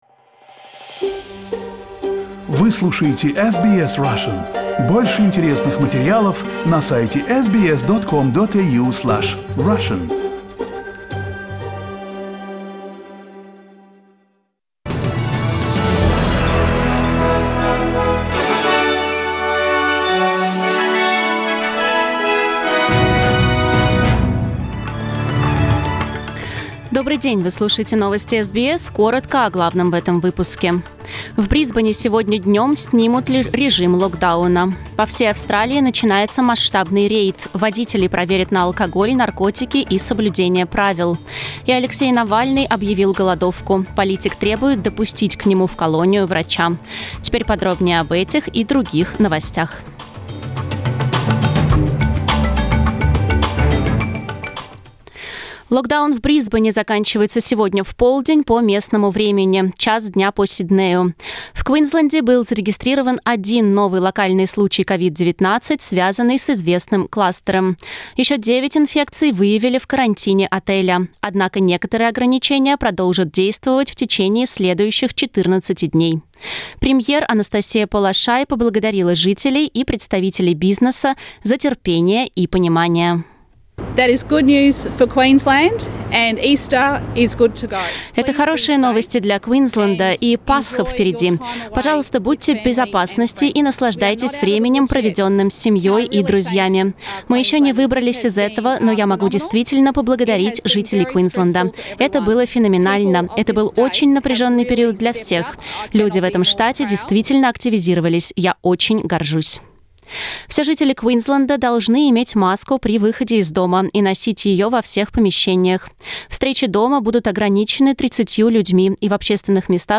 Новостной выпуск за 1 апреля